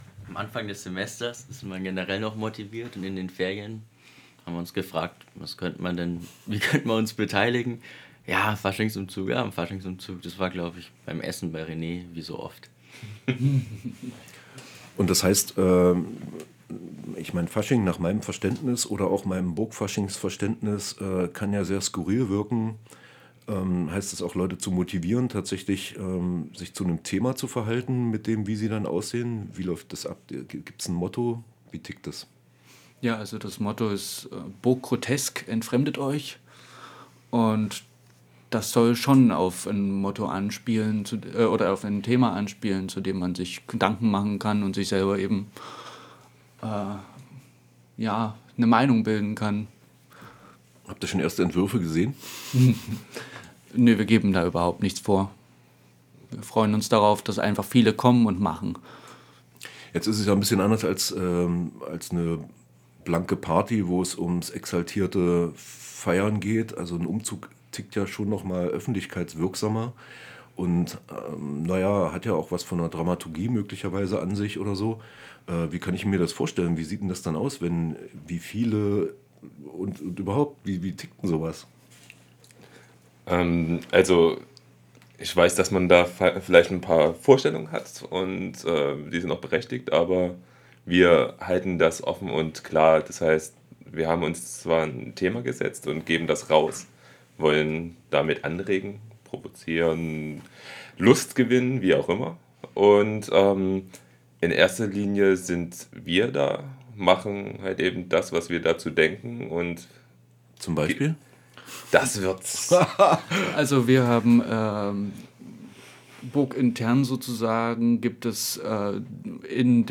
Wir haben mit den Organisatorinn*en vom StuRa der BURG gesprochen.